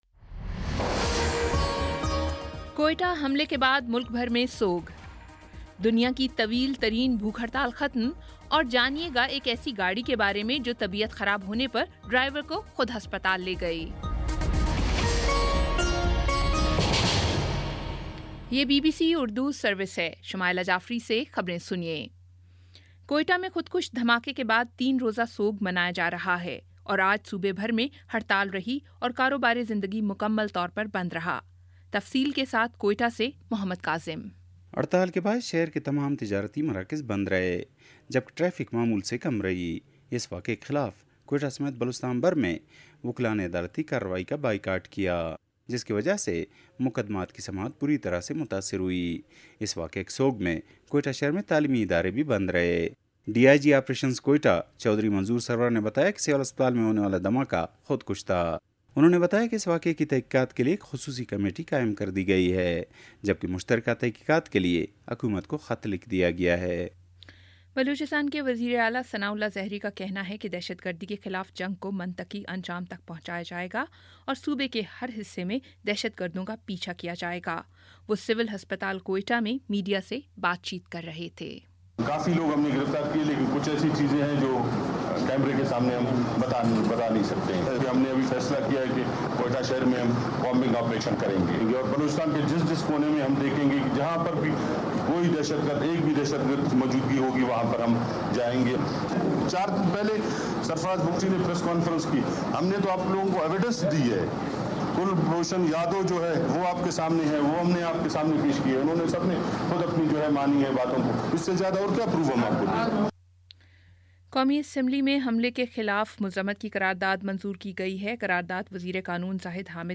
اگست 09 : شام چھ بجے کا نیوز بُلیٹن